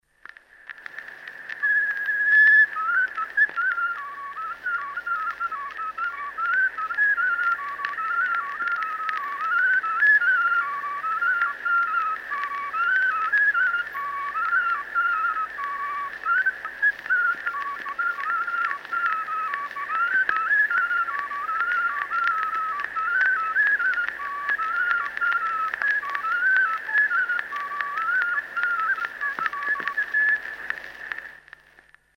Kitsesarvelugu 2